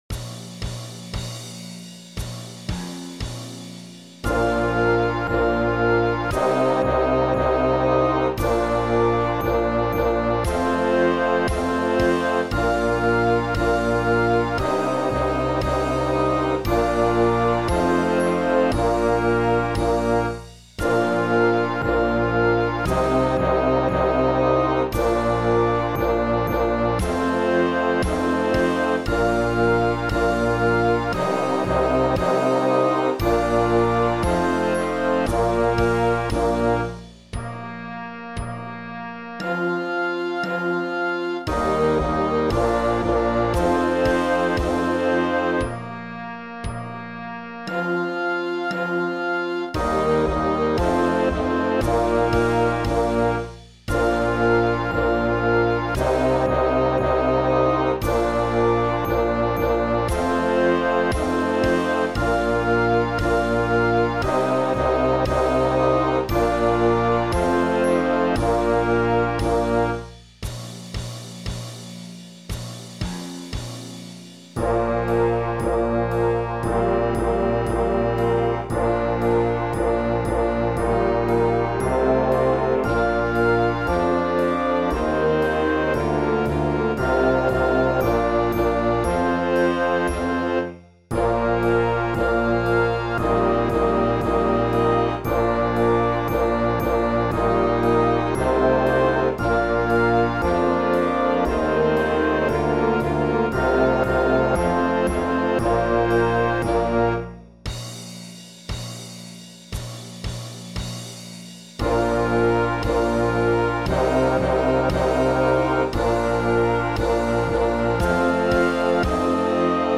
Beginner Concert Band  31M1